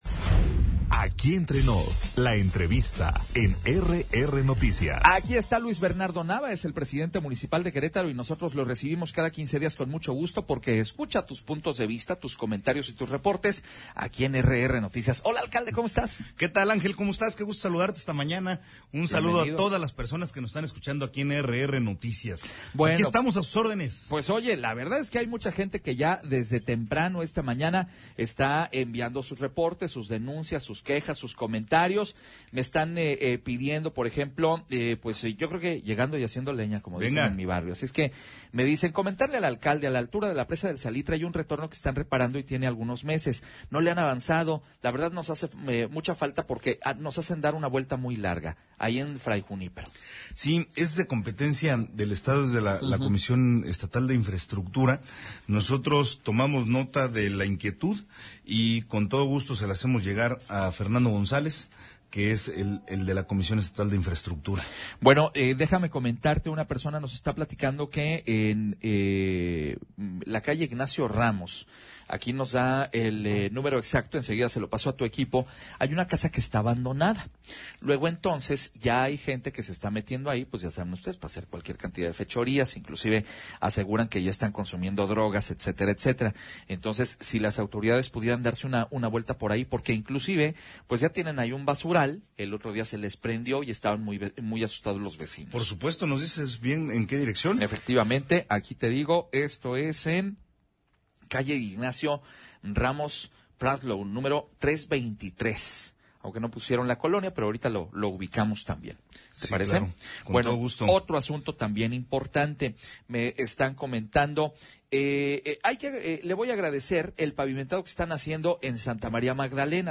Luis Nava atiende llamadas en RR Noticias
ENTREVISTAALCALDE.mp3